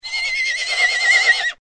Horse Neigh Sound Effect Free Download
Horse Neigh